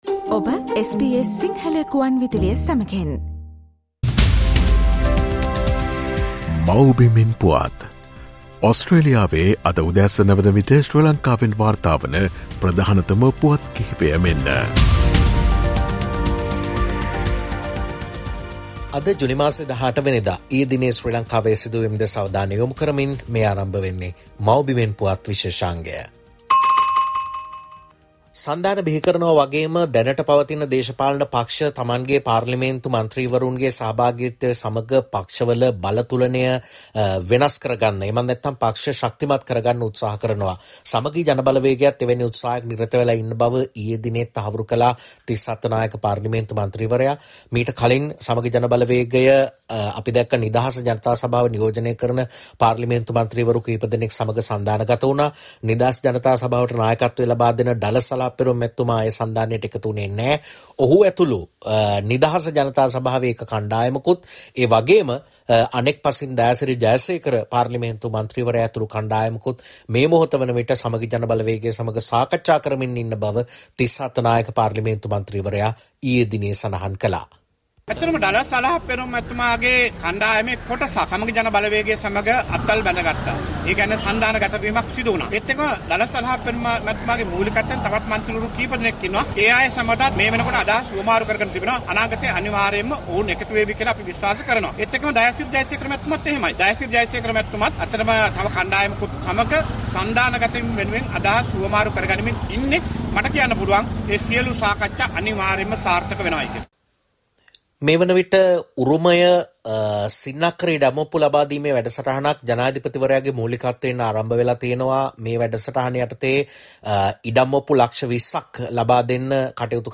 SBS Sinhala featuring the latest news reported from Sri Lanka